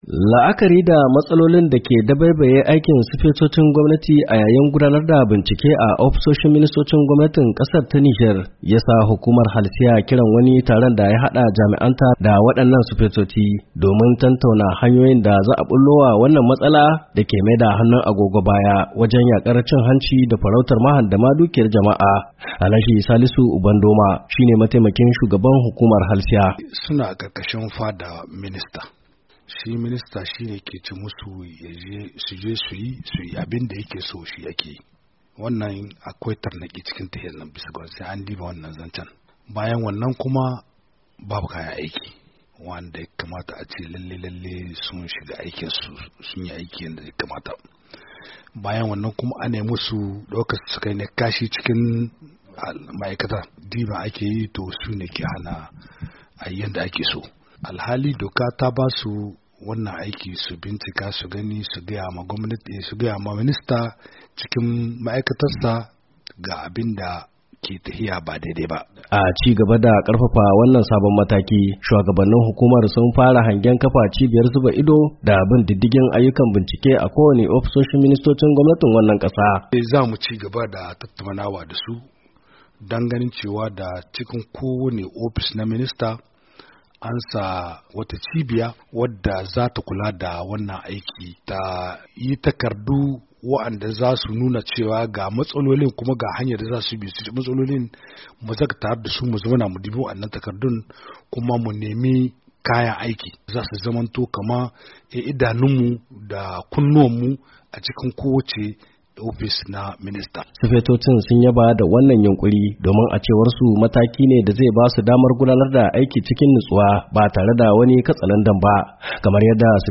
Saurare cikakken rahoton